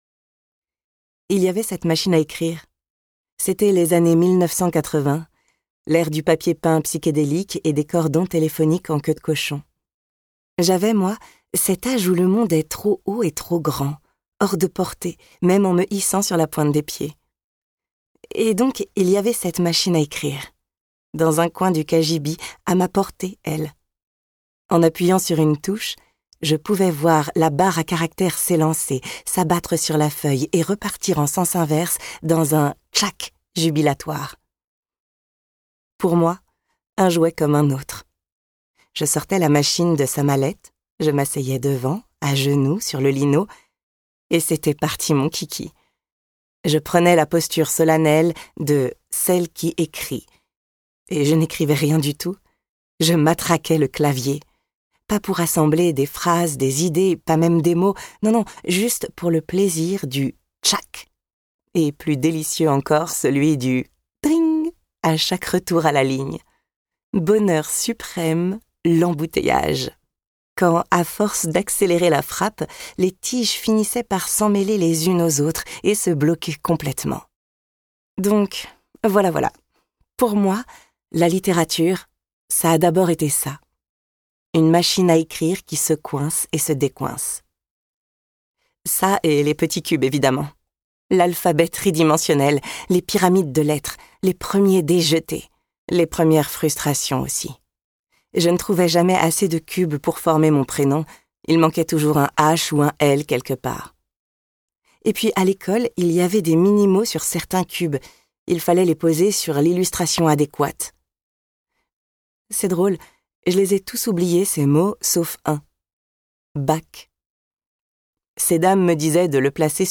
Extrait gratuit - Secrets d'écriture : Et l'imagination prend feu de Christelle Dabos
Avec un enthousiasme communicatif